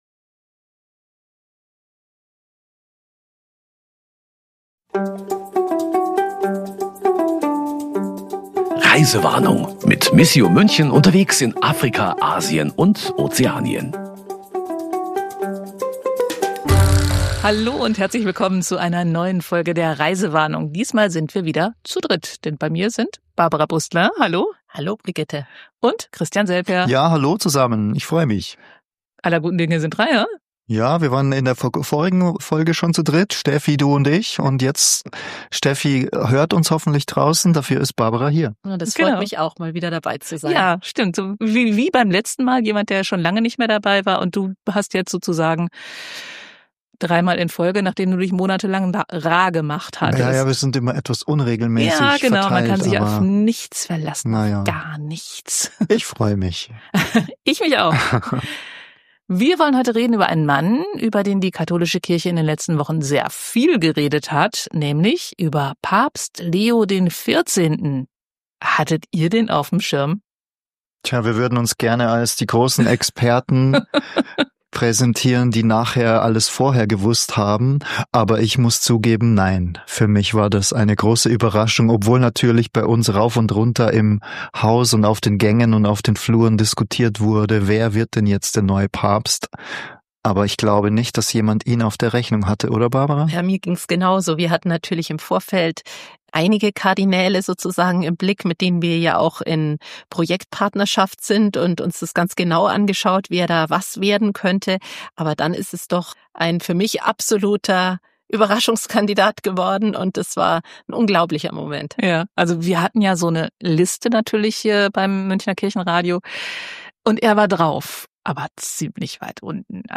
In „Reisewarnung“ erzählen die Reporterinnen und Reporter, was sie auf ihren Reisen erleben. Es geht um Autopannen und verspätete Flugzeuge, um schlaflose Nächte unterm Moskitonetz, und das eine oder andere Experiment im Kochtopf ist auch dabei.